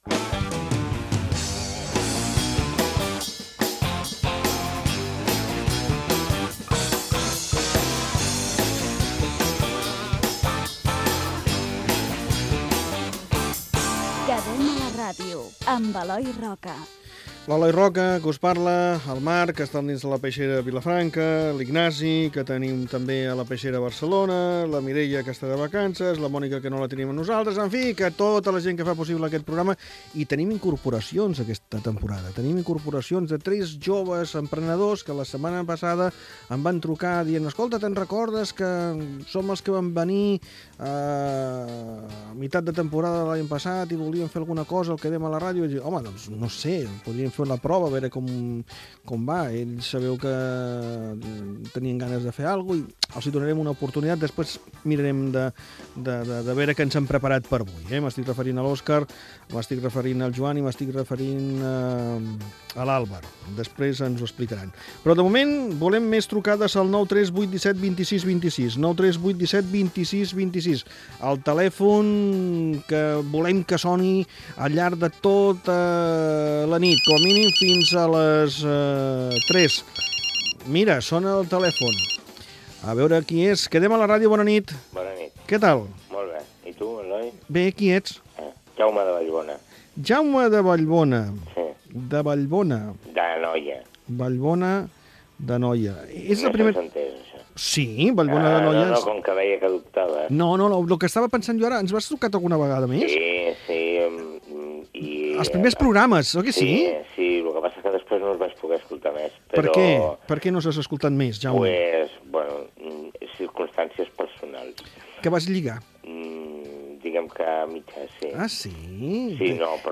42ec9c0c5b0978d1bdaae2f000d7c9c8f073b380.mp3 Títol COM Ràdio Emissora Ràdio Vilafranca Cadena COM Ràdio Titularitat Pública municipal Nom programa Quedem a la ràdio Descripció Careta, equip i inici del programa de participació per trobar parella. Gènere radiofònic Participació